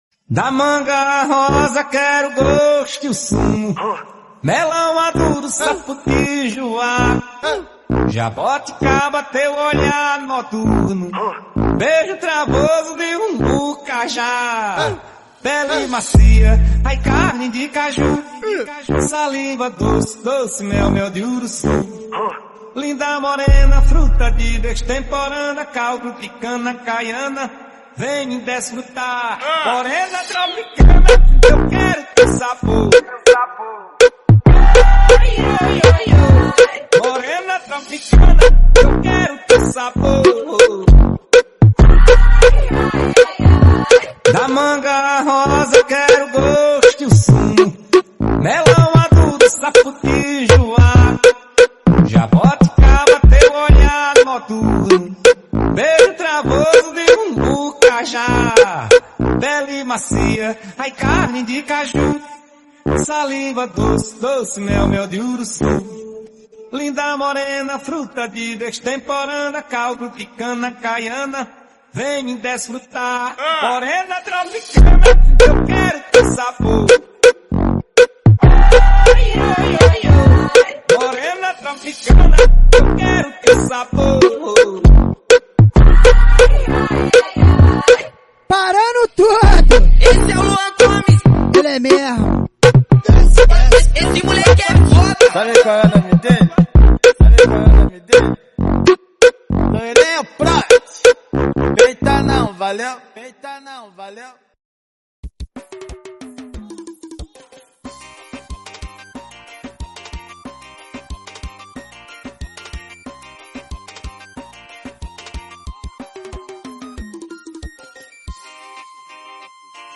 2024-06-22 18:19:12 Gênero: Reggae Views